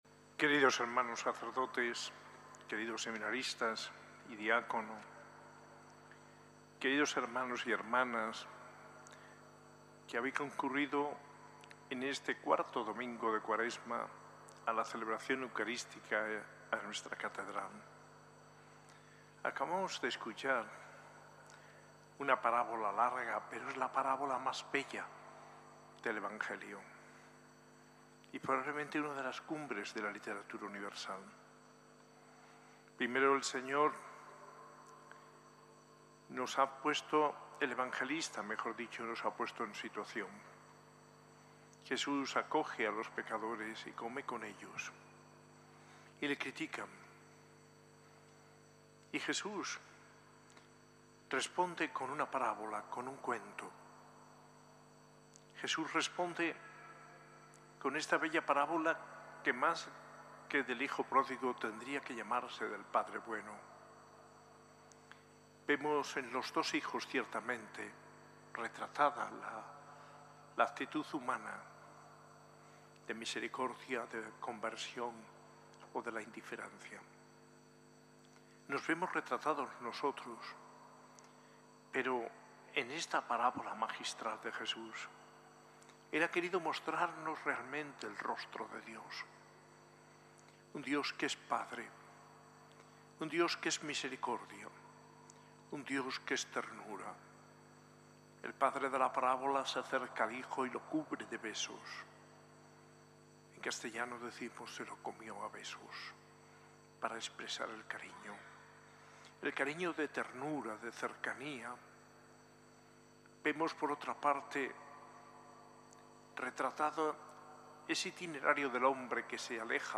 Homilía del arzobispo de Granada, Mons. José María Gil Tamayo, en el IV Domingo de Cuaresma, en la S.A.I Catedral el 30 de marzo de 2025.